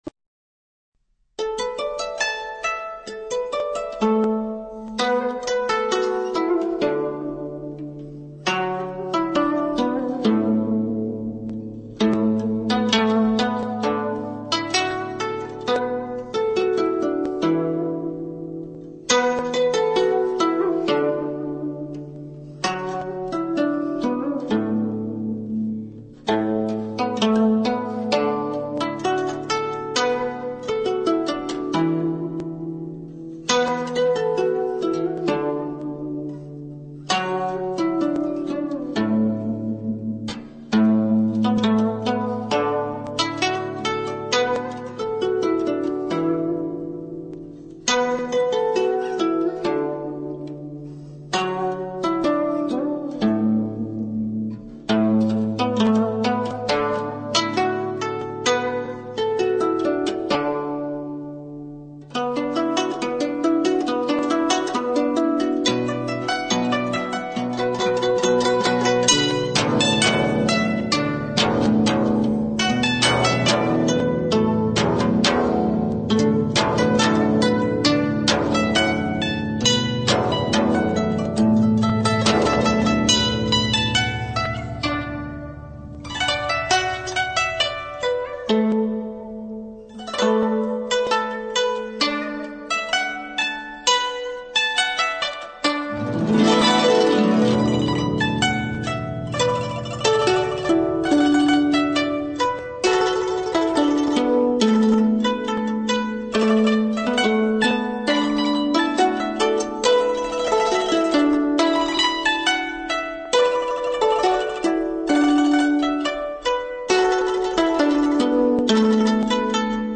背景音乐